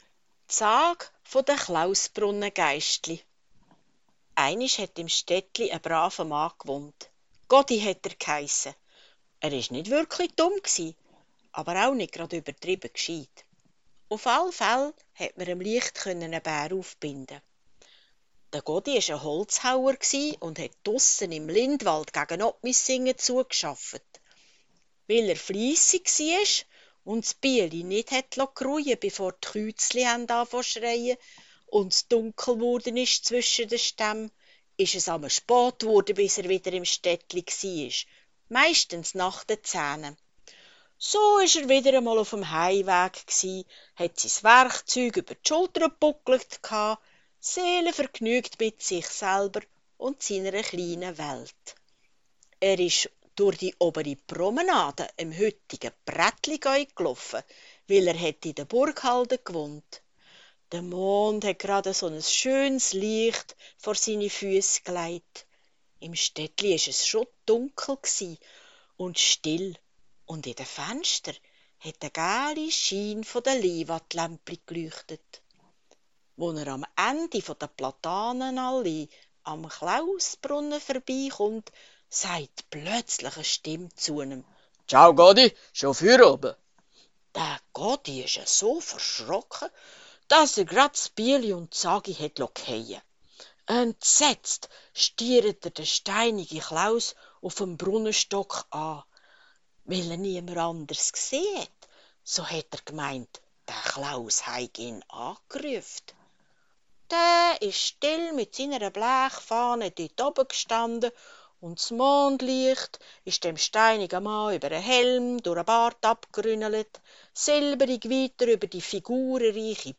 Sagenerzählung
2022_Klausbrunnen_Sage_Erzaehlung.mp3